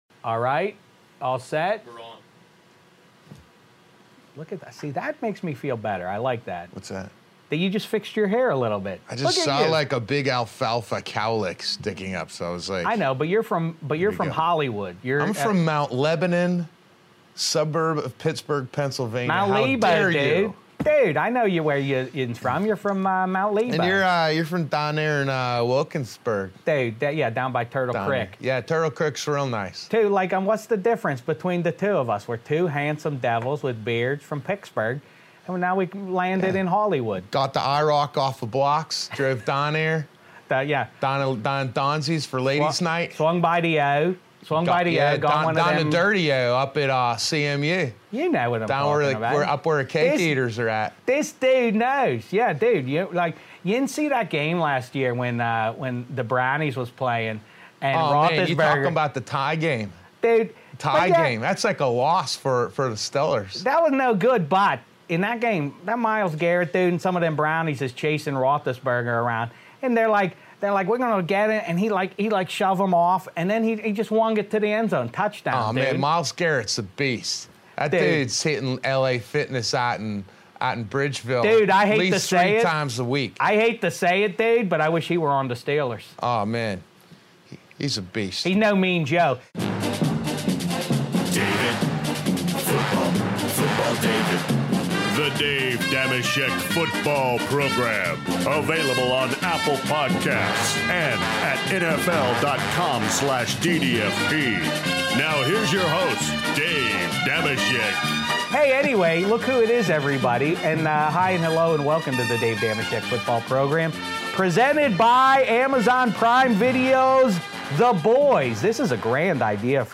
Dave Dameshek leaves Studio 66 to visit the new and improved Stage 5 to welcome the great Joe Manganiello. The two native Pittsburgh-ers hit on a wide range of topics including - Joe's new movie Bottom of the Ninth (6:45), Ben Roethelisberger's pass to Santonio Holmes in SBXLIII (26:35), his worst Steelers memories (22:50), how he got into acting after being a high school phenom athlete (10:25), and what it was like filming Trueblood and Magic Mike (40:20).